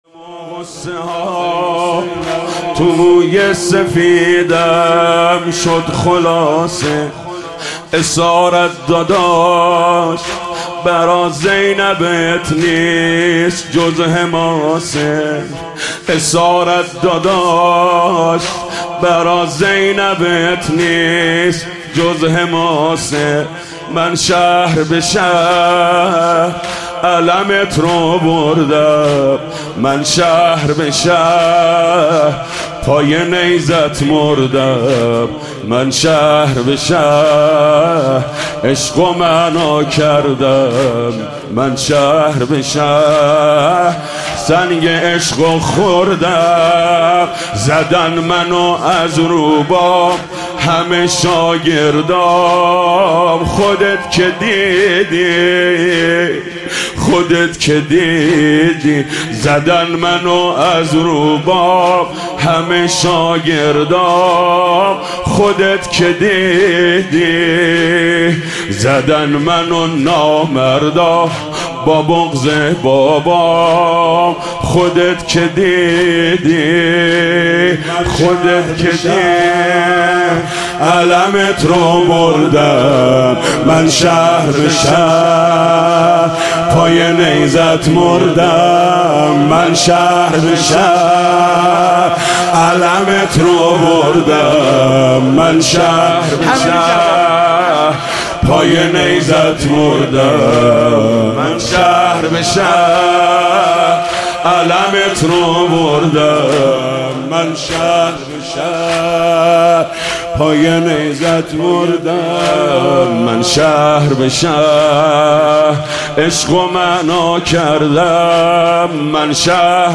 مداحی
شب اربعین حسینی
هیات رایة العباس(ع) تهران